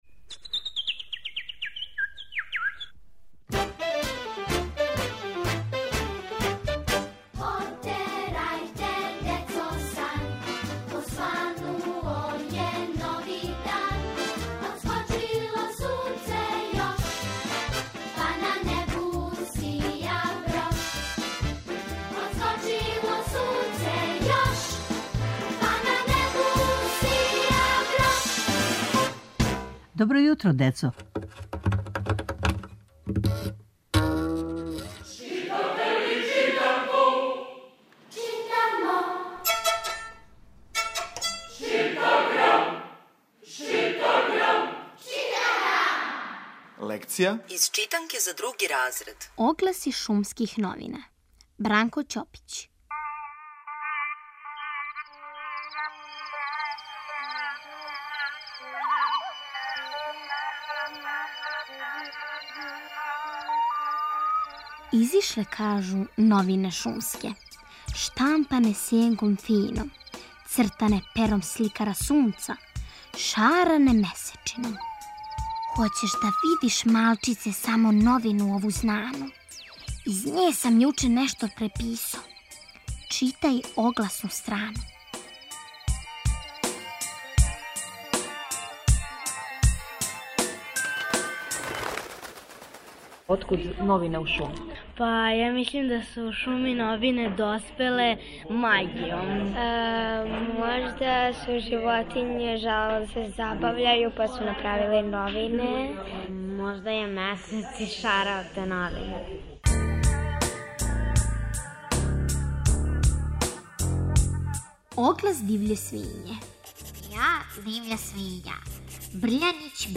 Читанка за слушање. Ове недеље - други разред, лекција: "Шумски оглас", Бранко Ћопић.